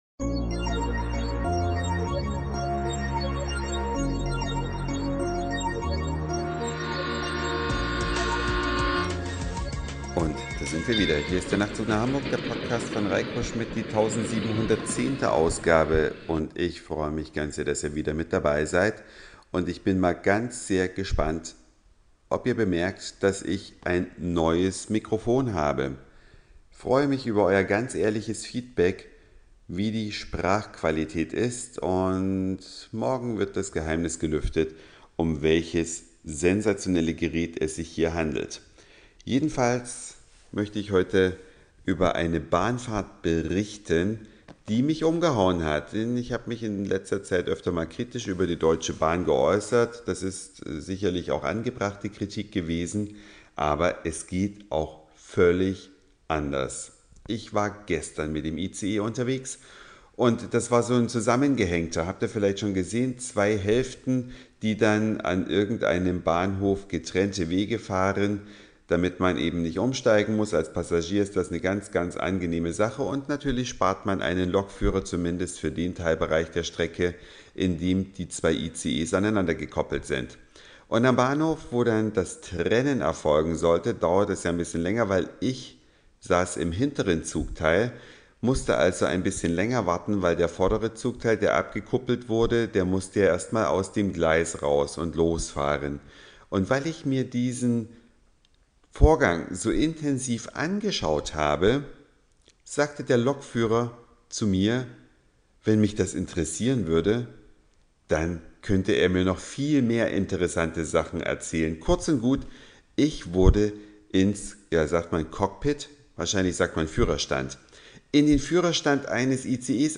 Eine Reise durch die Vielfalt aus Satire, Informationen, Soundseeing und Audioblog.
Wie ist der Klang? Ungewöhnlicher Reiseplatz in ICE NnH bei